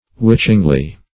witchingly - definition of witchingly - synonyms, pronunciation, spelling from Free Dictionary